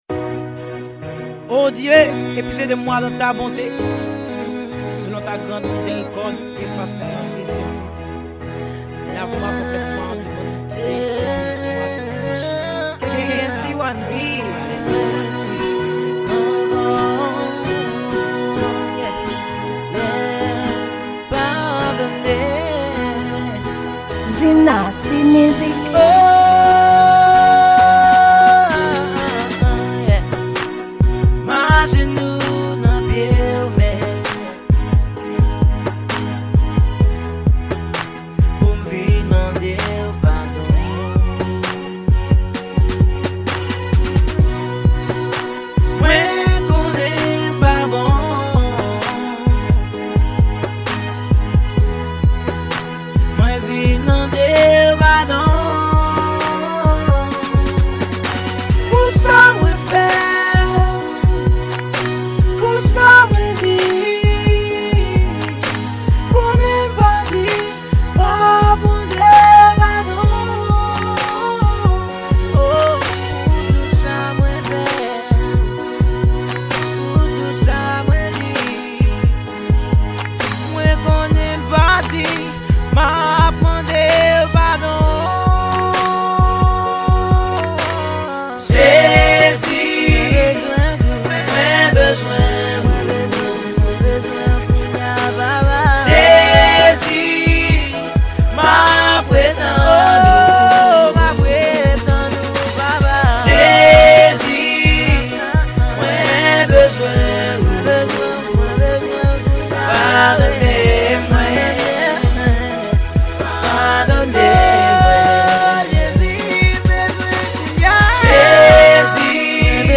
Genre: Gospel